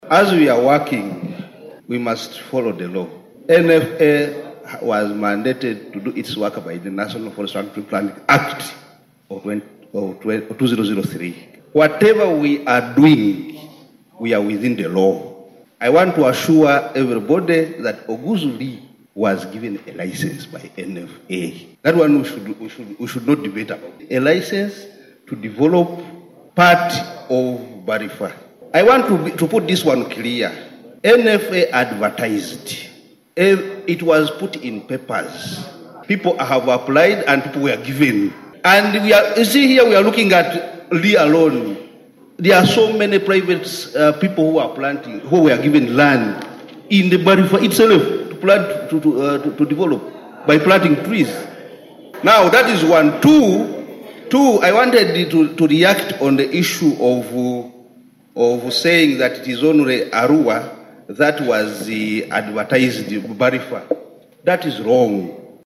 A representative from the National Forestry Authority spoke in defense of Oguzu, asserting that the MP had followed the appropriate channels to secure the license for the Barifa forest development.